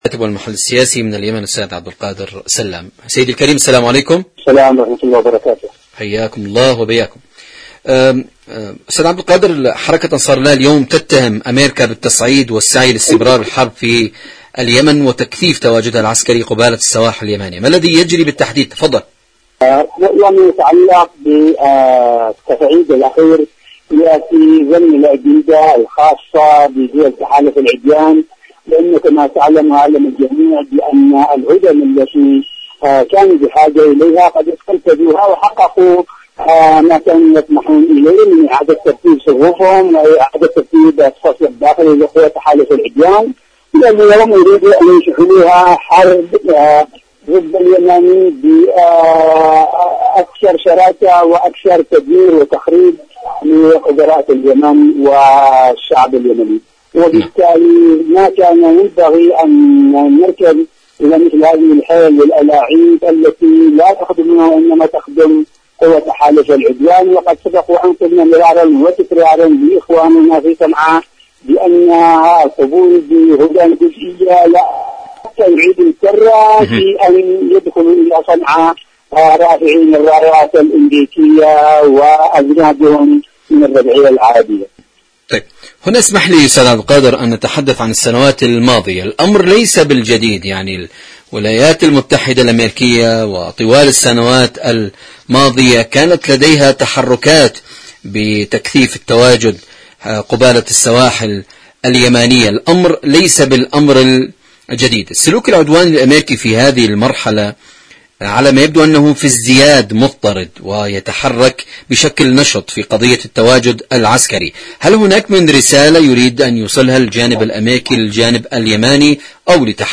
إذاعة طهران-اليمن التصدي والتحدي: مقابلة إذاعية